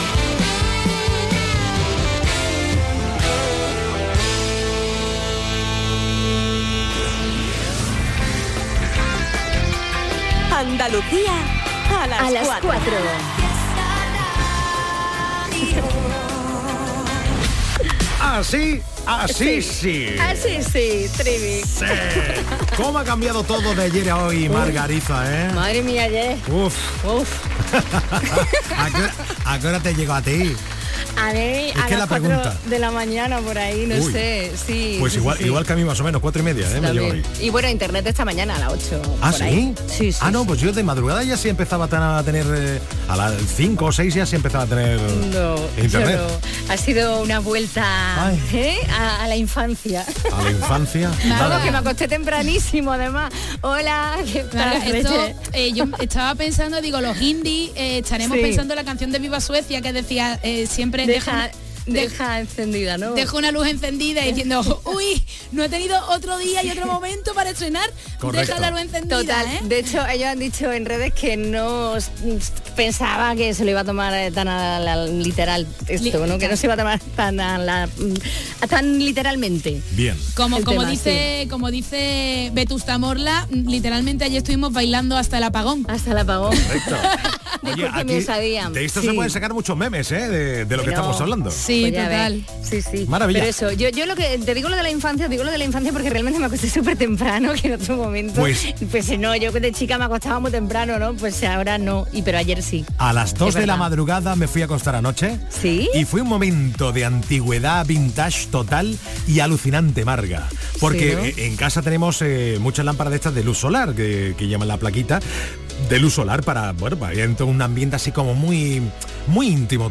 Vive tus tardes con la mejor música